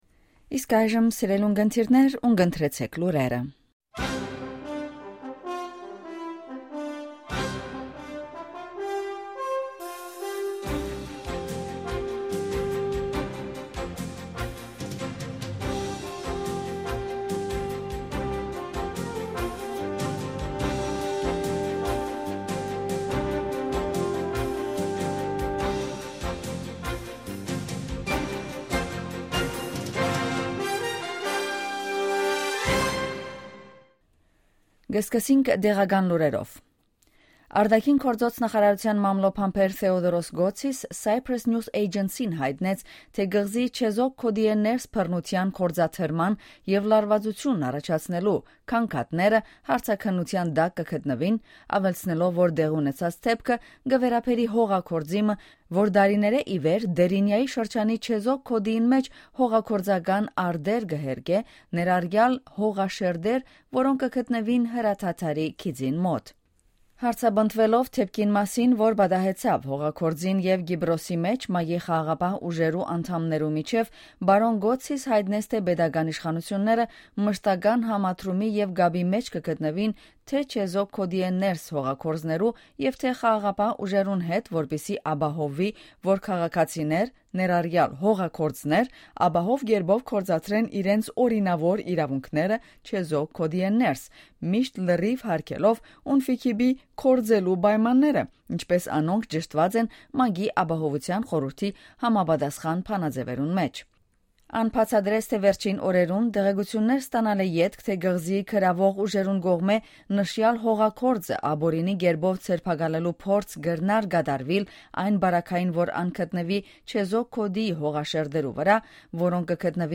Ειδήσεις στα Αρμένικα - News in Armenian
Daily News in Armenian.